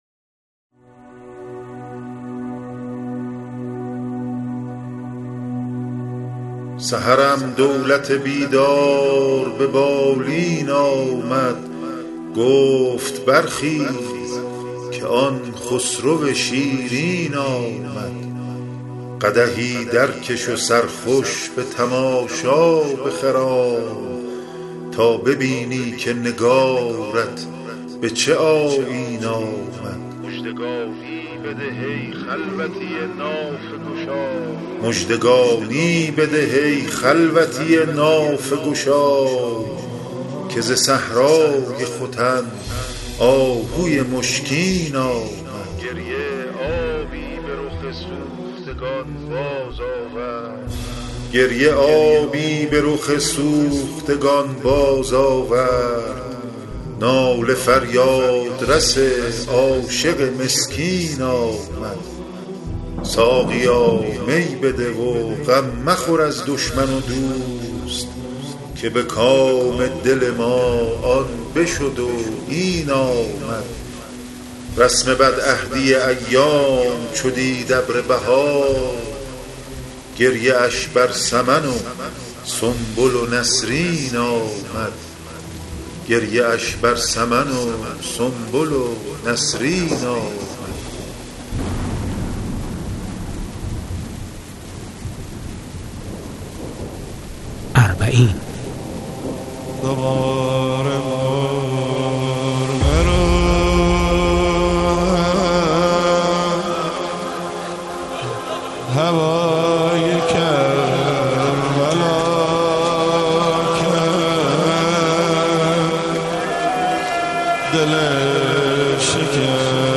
✦ پژوهشگر و راوی
✦ نویسنده و گوینده متن‌های ادبی